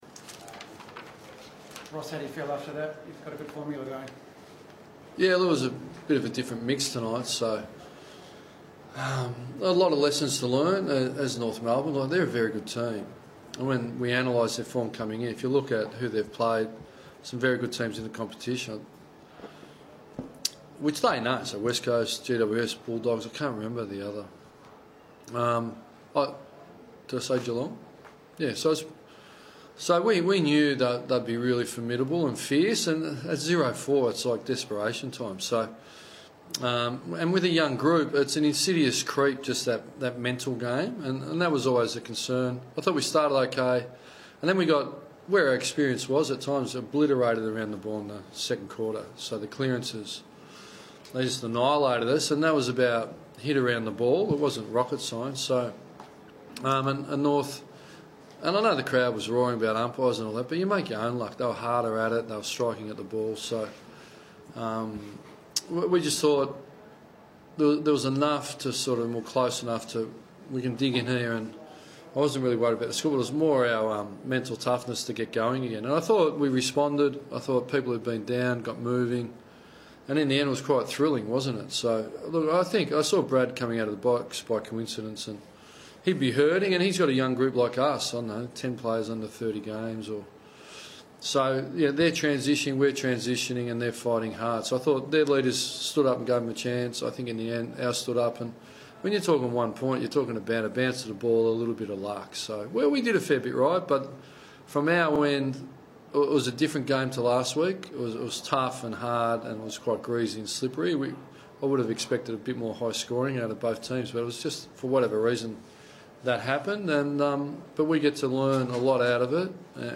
Lyon speaks to the media after Freo's win over the Roos.